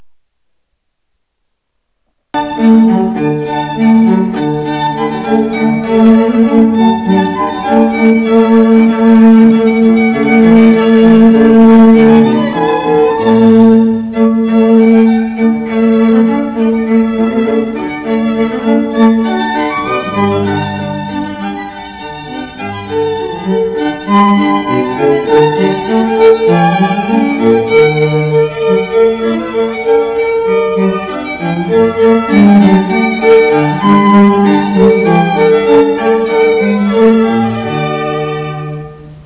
Serenade String Quartet
Baroque Music Samples